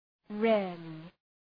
Προφορά
{‘reərlı}